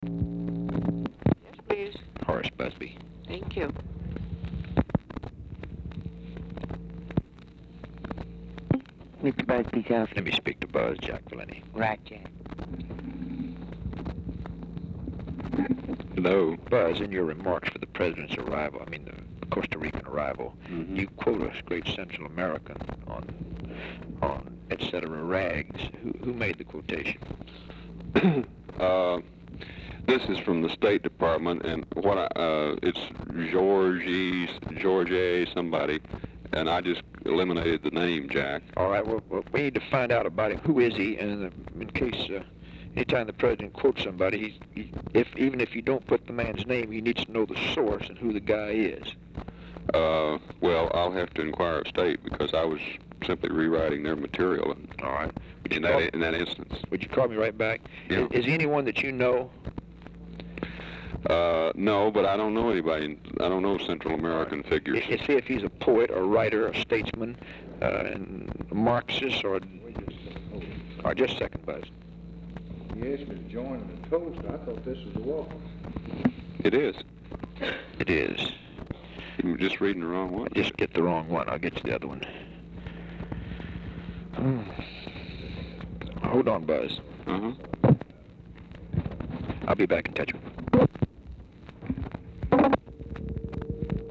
Telephone conversation # 3958, sound recording, LBJ and HORACE BUSBY, 6/30/1964, time unknown | Discover LBJ
CALL IS FROM VALENTI TO BUSBY BUT LBJ IS AUDIBLE IN BACKGROUND OFFICE CONVERSATION
Format Dictation belt
Location Of Speaker 1 Oval Office or unknown location